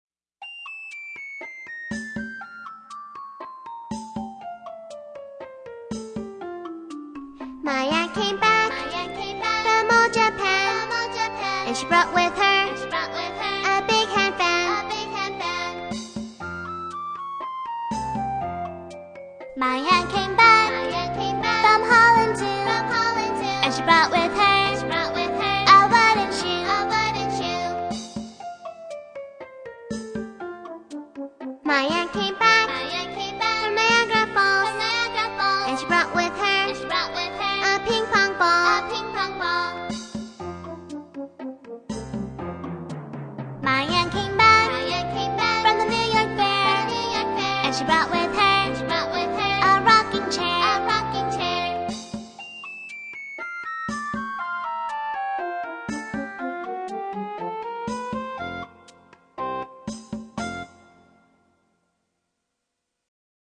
在线英语听力室英语儿歌274首 第138期:My aunt came back的听力文件下载,收录了274首发音地道纯正，音乐节奏活泼动人的英文儿歌，从小培养对英语的爱好，为以后萌娃学习更多的英语知识，打下坚实的基础。